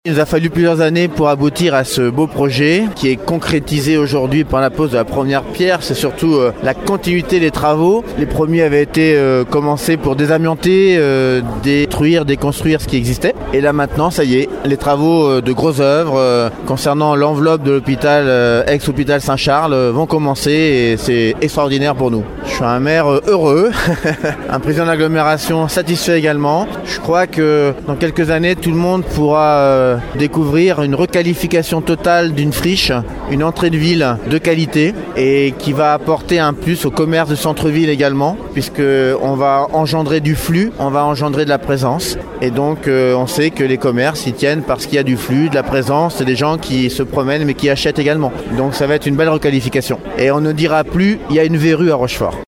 Lors de la pose vendredi.
Un projet ambitieux qui doit permettre de redonner vie à un quartier en souffrance depuis l’abandon du site. Ce que souhaite vivement le maire de la Ville et président de la Communauté d’Agglomération Rochefort Océan Hervé Blanché :